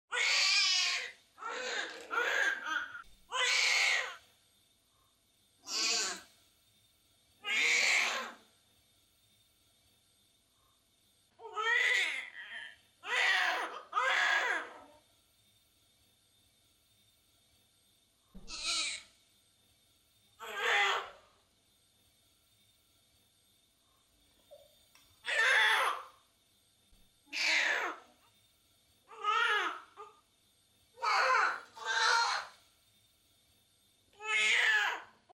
Плач новорожденного ребенка
Ребенок издает первый крик, плачет, хнычет, пукает и «заходится» в истерике.
Крик новорожденного на общем плане в роддоме.